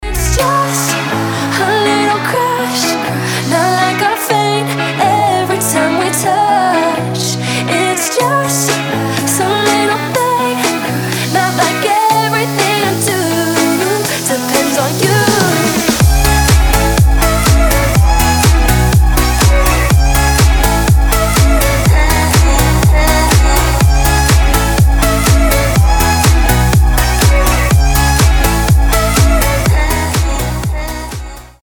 • Качество: 320, Stereo
женский вокал
deep house
dance
красивый женский голос